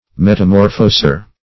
Metamorphoser \Met`a*mor"pho*ser\, n. One who metamorphoses.